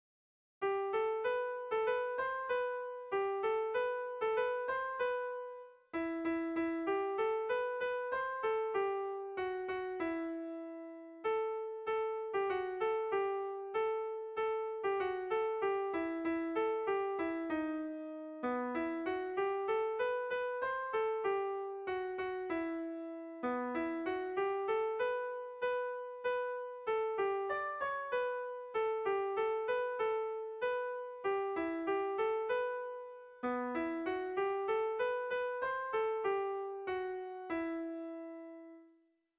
Bertso melodies - View details   To know more about this section
Tragikoa
Arraioz < Baztan < Baztan Ibarra < Iruñeko Merindadea < Navarre < Basque Country